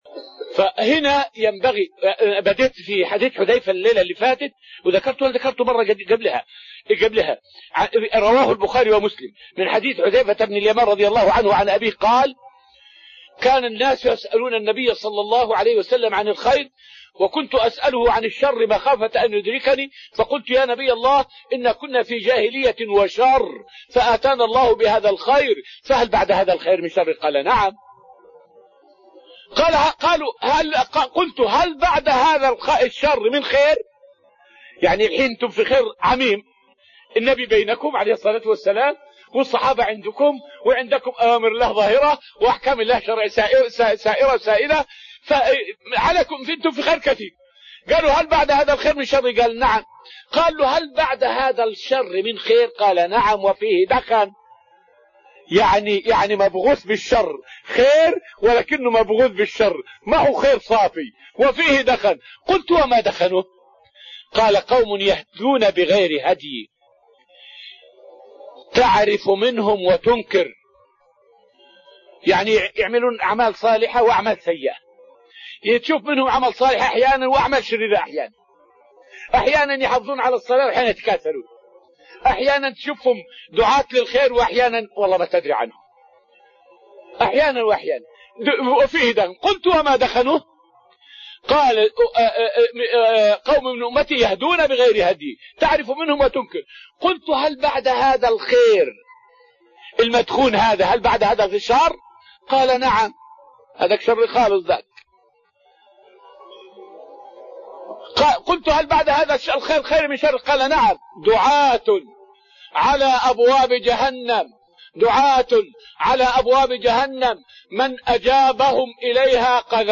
فائدة من الدرس السابع من دروس تفسير سورة النجم والتي ألقيت في المسجد النبوي الشريف حول قاعدة عند أهل العلم من العارفين بحديث رسول الله صلى الله عليه وسلم.